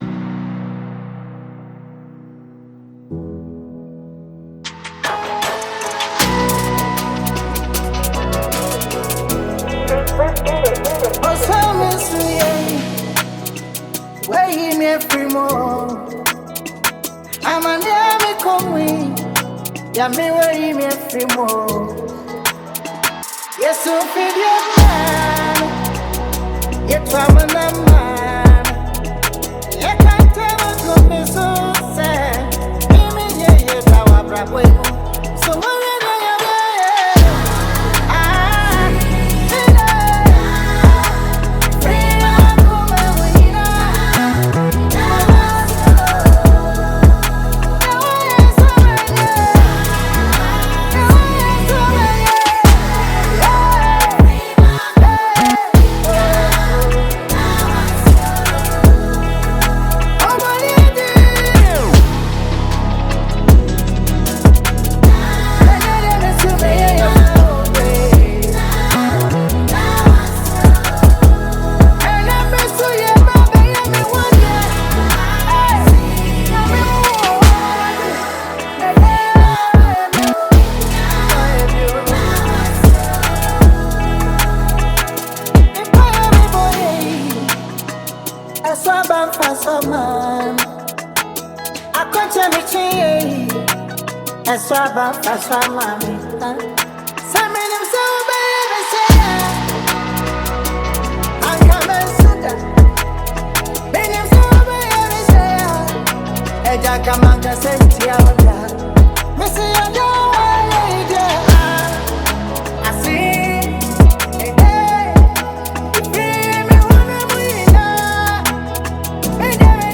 a refreshing blend of spirituality and traditional sounds